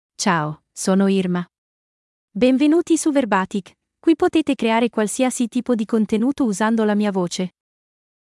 FemaleItalian (Italy)
IrmaFemale Italian AI voice
Voice sample
Listen to Irma's female Italian voice.
Irma delivers clear pronunciation with authentic Italy Italian intonation, making your content sound professionally produced.